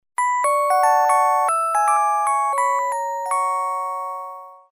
Index of /phonetones/unzipped/LG/KU5500/Message tones
Sentimental.mp3